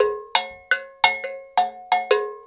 AFRO AGOGO.wav